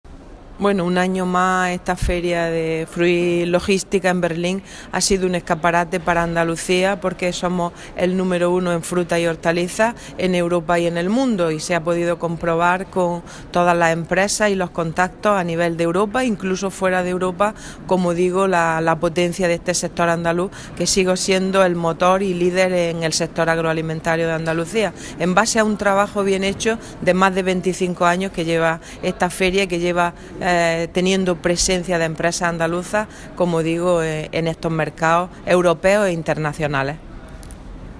Declaraciones consejera Fruit Logistica